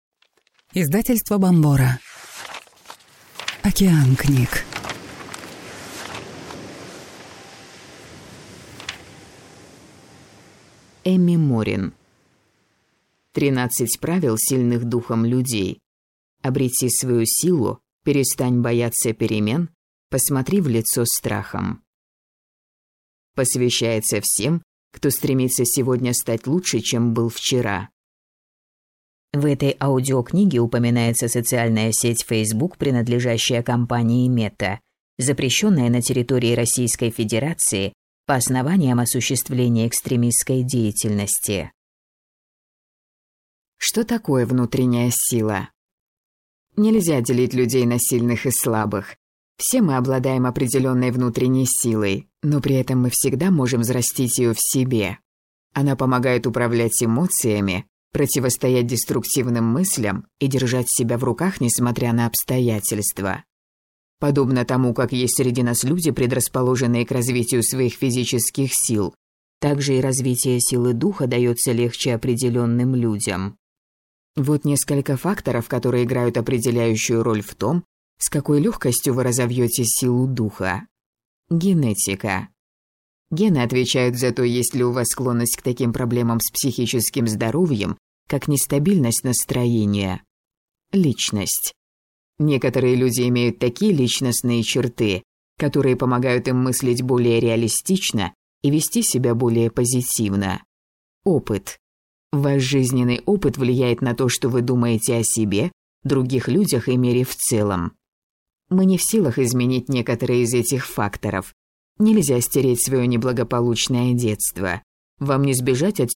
Аудиокнига 13 правил сильных духом людей. Обрети свою силу, перестань бояться перемен, посмотри в лицо страхам | Библиотека аудиокниг